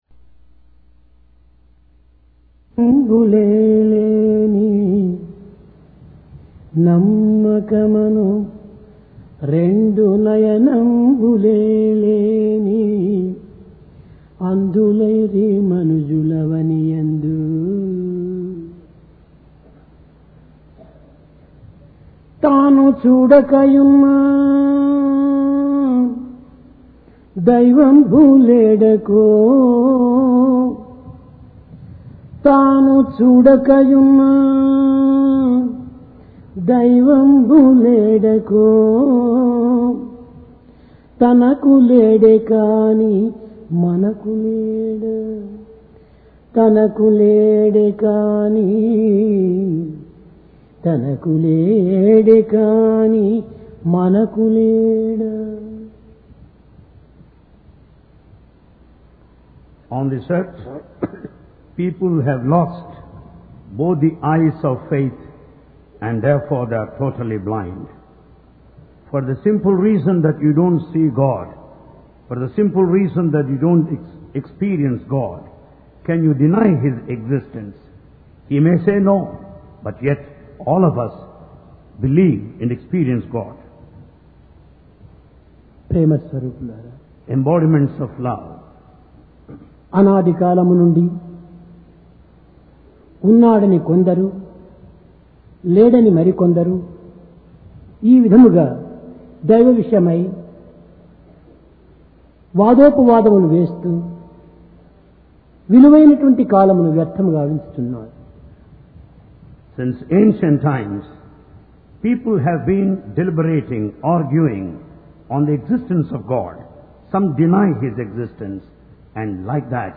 PRASHANTI VAHINI - DIVINE DISCOURSE 7 JULY, 1996
Place: Prashanti Nilayam